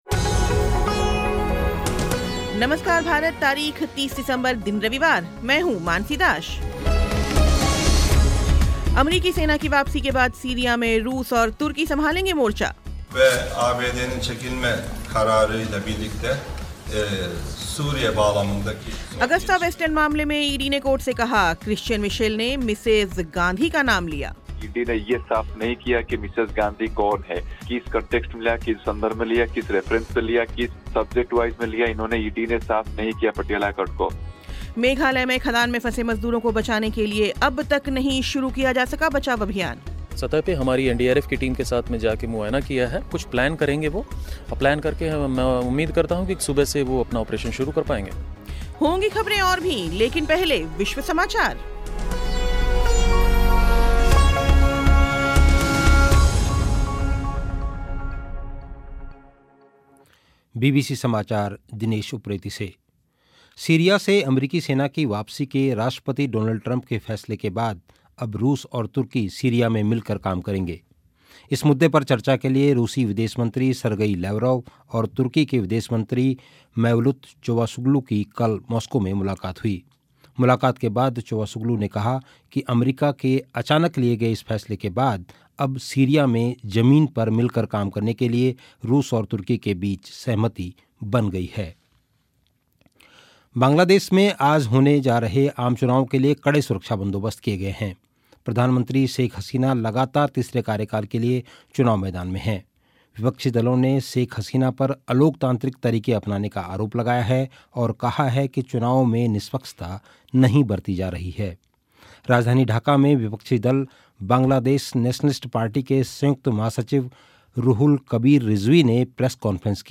खेल समाचार